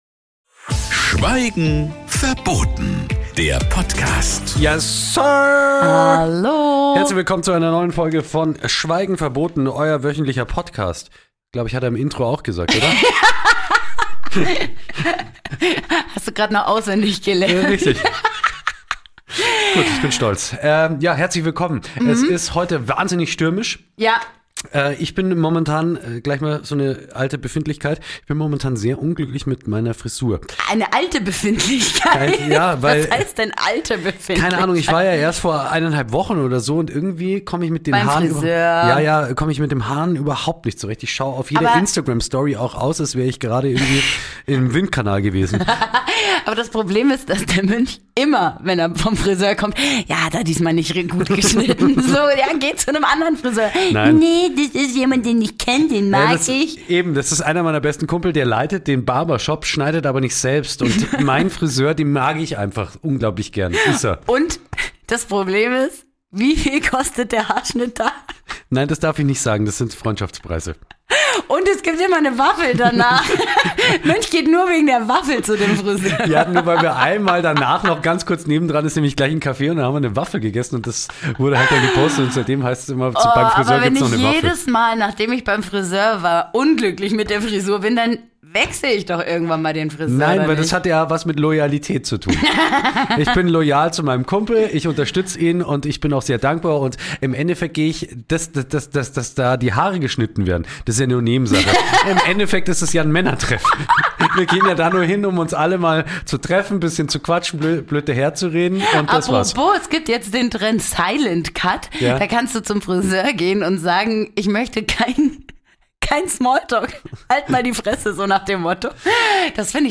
Sie talken wöchentlich am Freitag so, wie ihnen der Schnabel gewachsen ist.